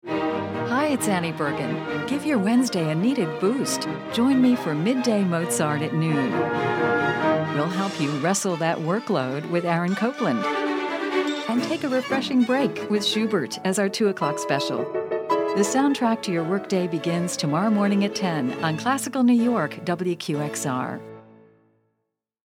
WQXR promo for Midday.